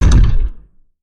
etfx_shoot_energy02.wav